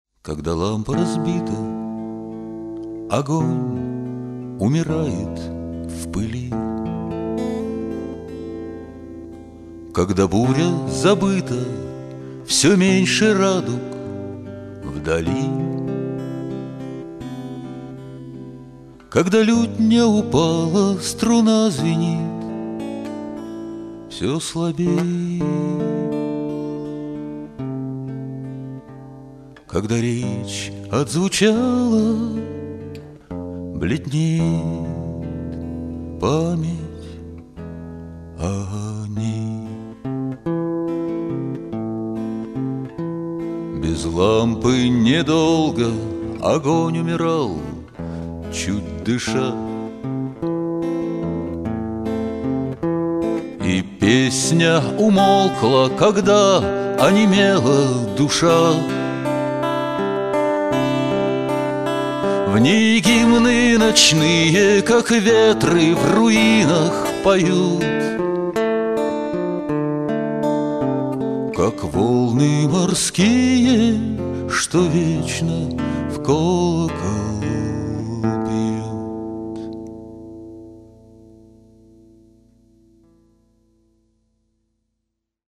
Мюзикл
на сцене дворца детского и юношеского творчества
Сценарий и песни из спектакля: